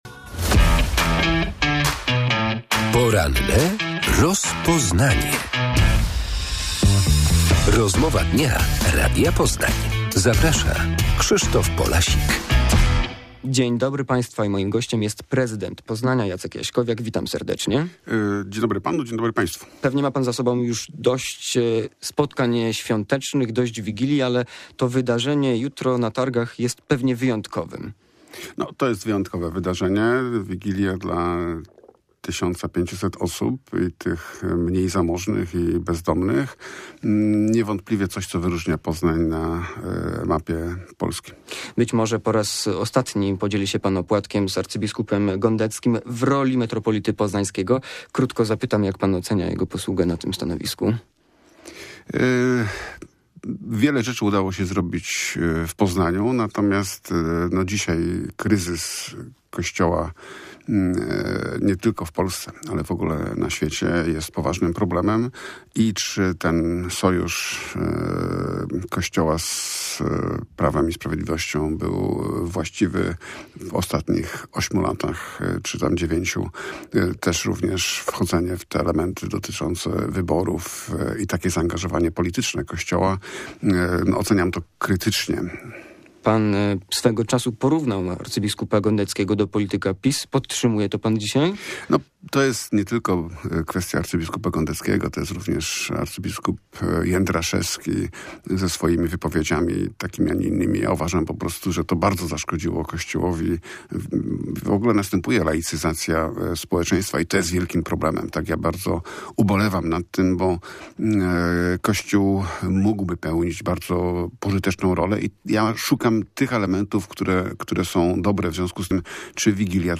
Wigilia Caritas i pierwszy od pięciu lat miejski sylwester odbędą się na Międzynarodowych Targach Poznańskich. Z prezydentem Poznania Jackiem Jaśkowiakiem rozmawiamy także o sytuacji finansowej oraz przyszłości MTP.